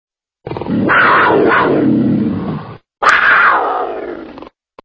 Wildcat Growl Ringtone
wildcatgrowl.mp3